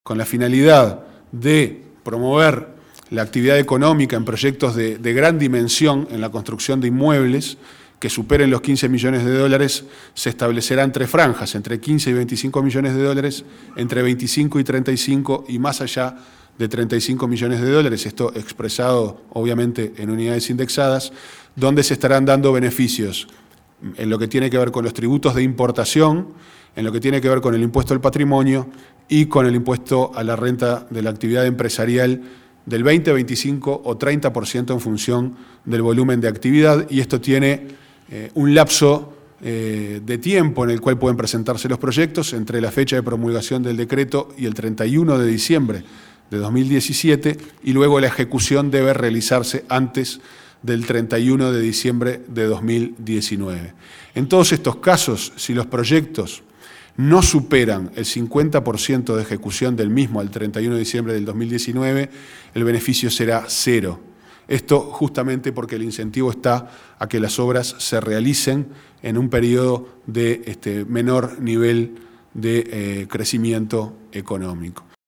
Al término del Consejo de Ministros del pasado lunes, Ferreri explicó que los beneficios se establecerán por franjas, dependiendo del nivel de inversión.